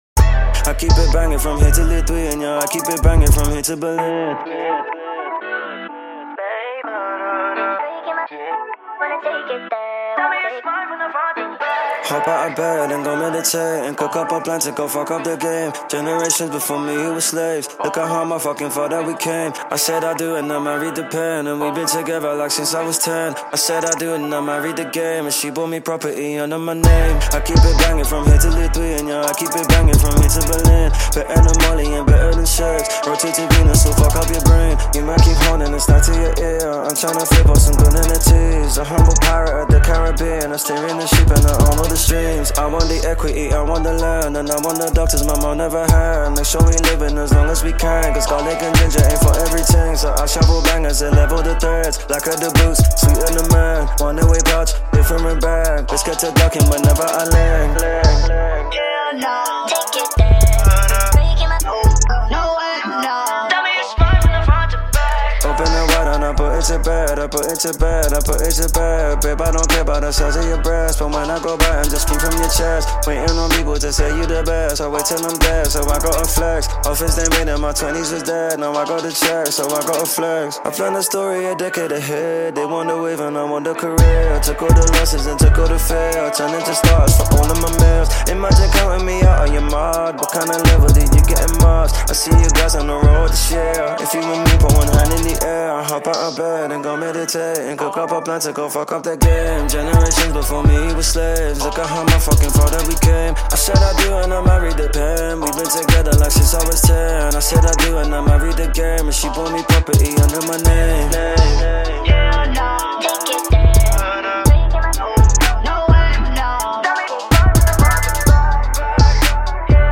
I wrote a freestyle